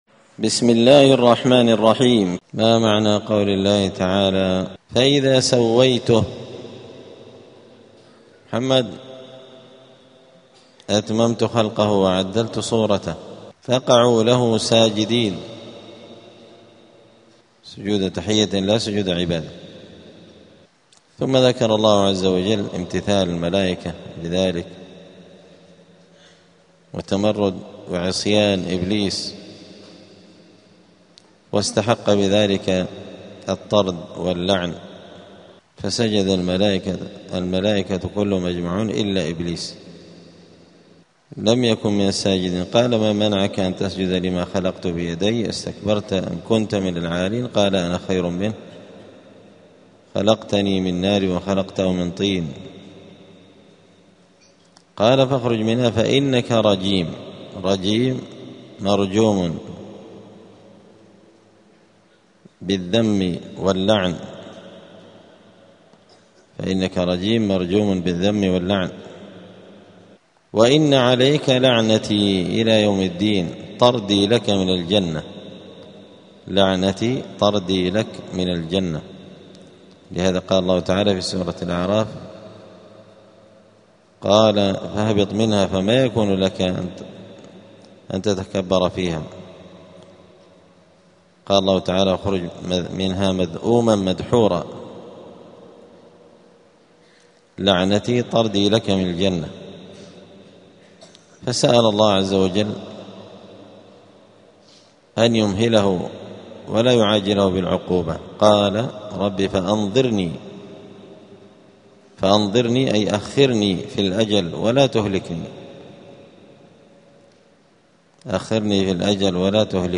الأحد 18 جمادى الأولى 1447 هــــ | الدروس، دروس القران وعلومة، زبدة الأقوال في غريب كلام المتعال | شارك بتعليقك | 9 المشاهدات